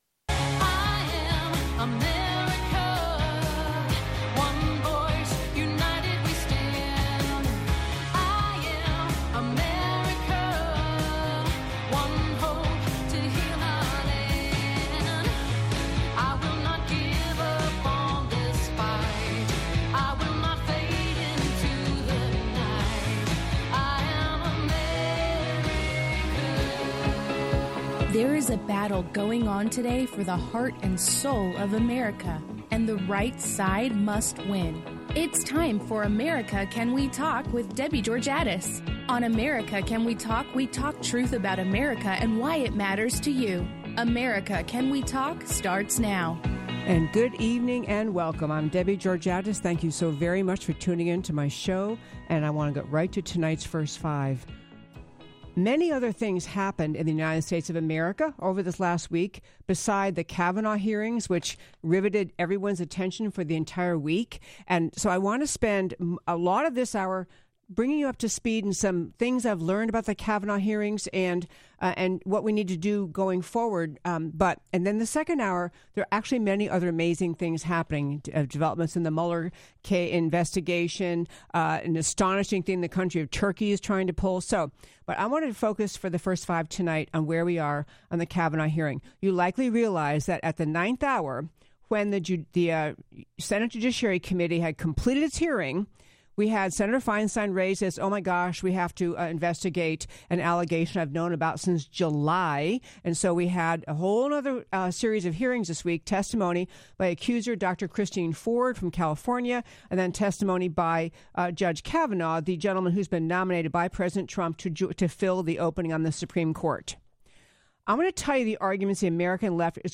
Guest interview – Kelli Burton, Texas State Senator